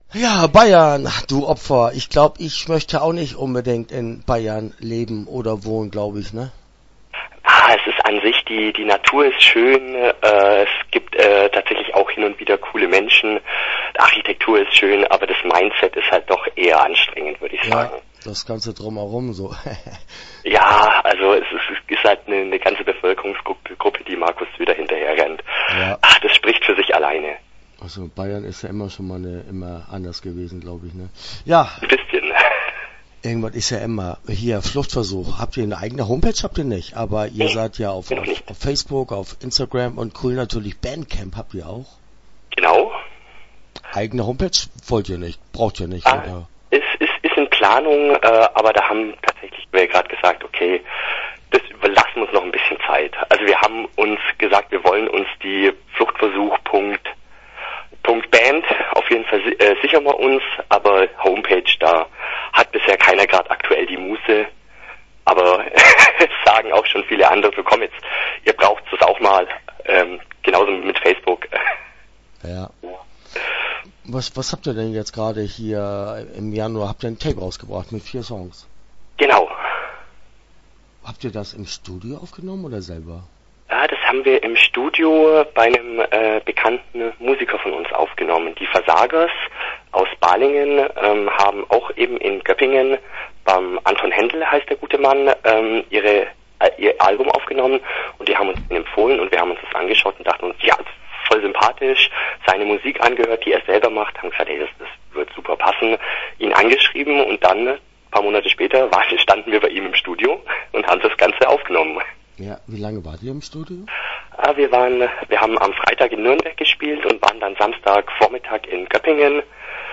Start » Interviews » Fluchtversuch